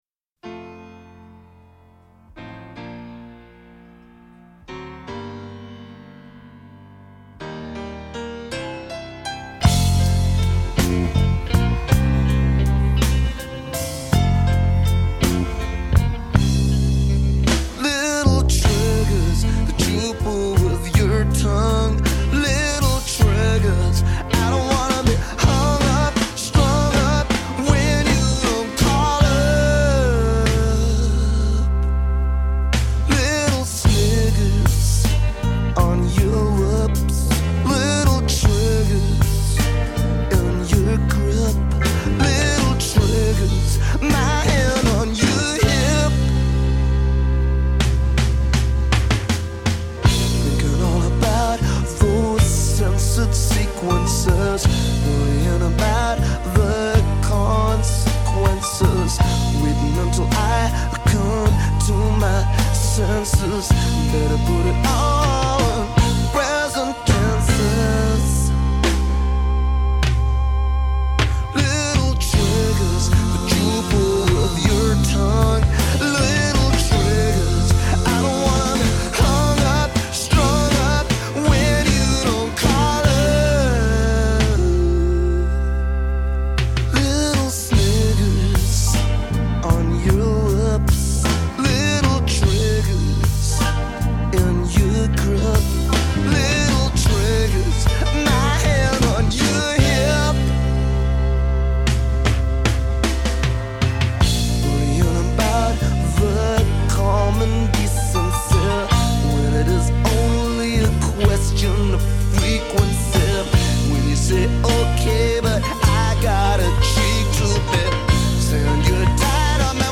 surprised me by being that slow burner I was looking for.